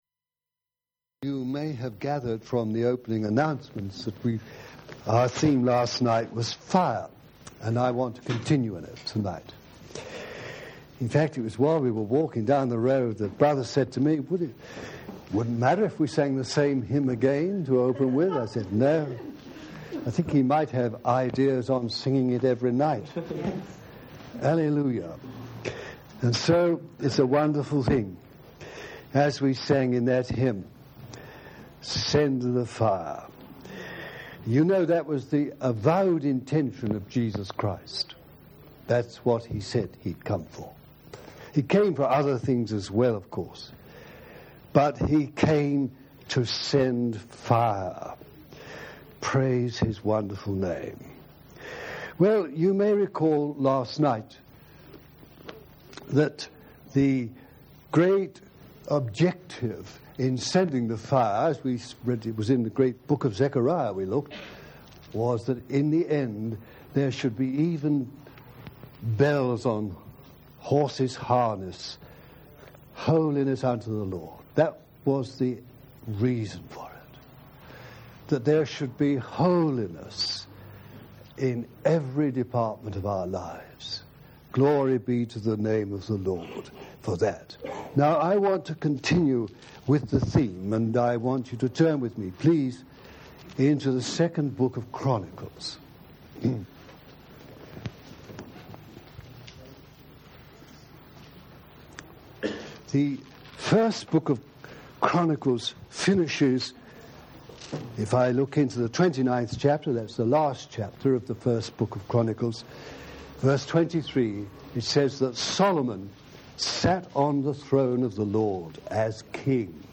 Message: “Choose Fire
over a period of 4 years at Rora House CF, Devon; Devonshire Rd CF Liverpool; the Longcroft CF, Wirral